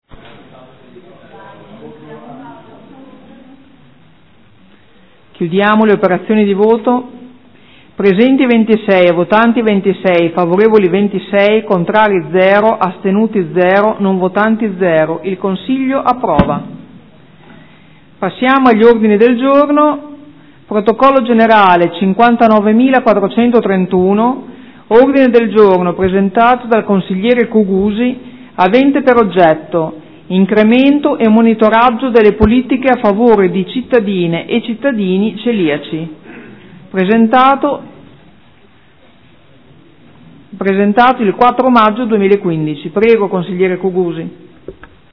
Seduta del 21/05/2015 Mette ai voti.
Presidentessa